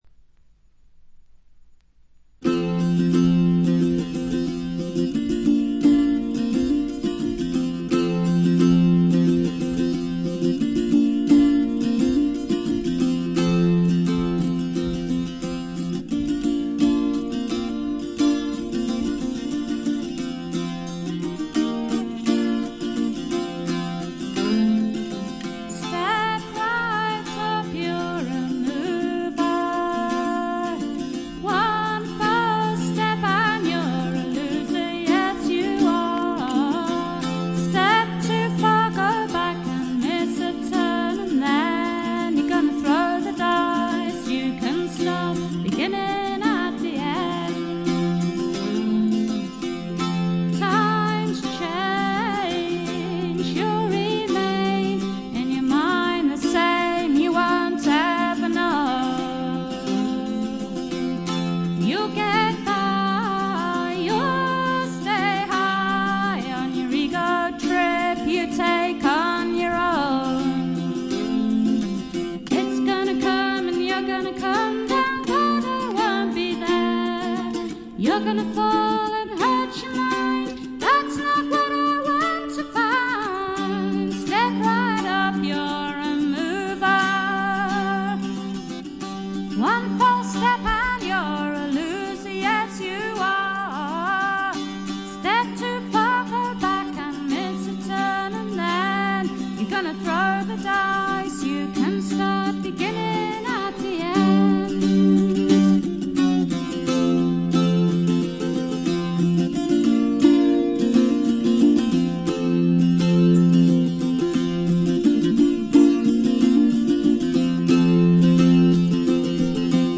演奏面は彼女自身が奏でるギター、ブズーキだけと非常にシンプルなもの。
アルバム全体はしっとりした雰囲気で、不思議な浮遊感があり少しくぐもったかわいい歌声が「夢の世界」を彷徨させてくれます。
試聴曲は現品からの取り込み音源です。